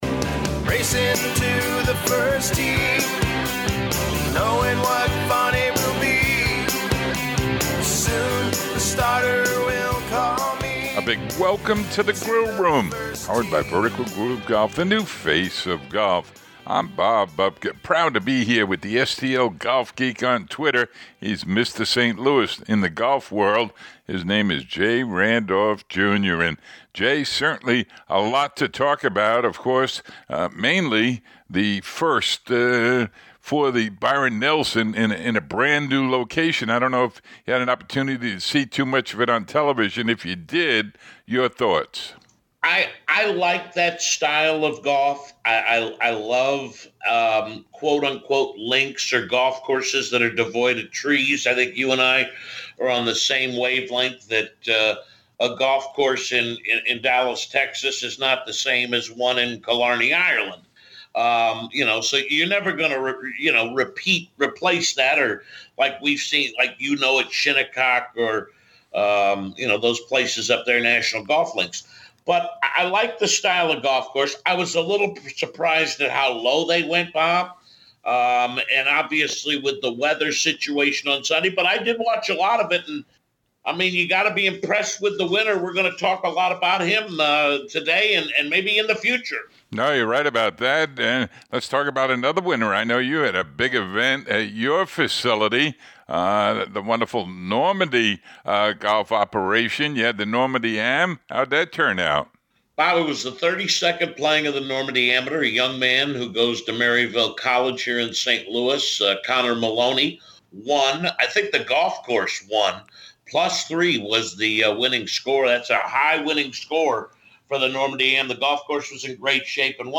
Feature Interviews